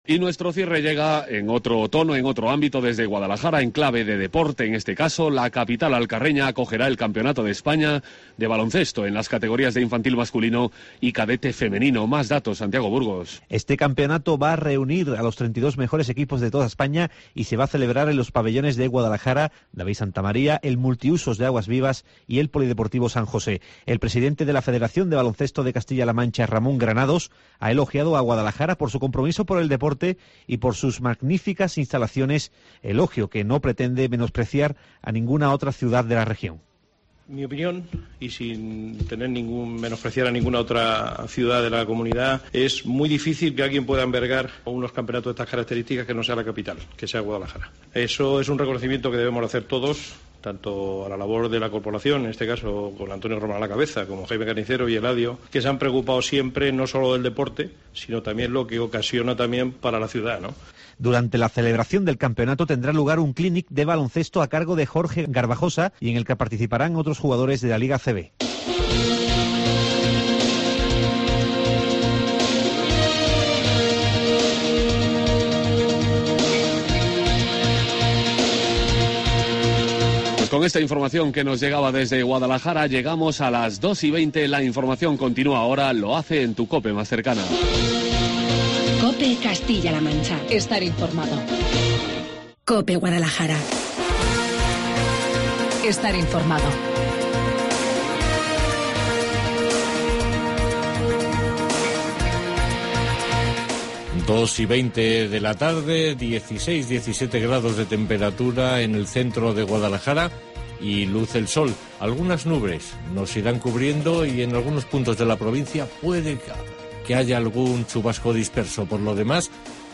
Informativo Guadalajara 21 DE MAYO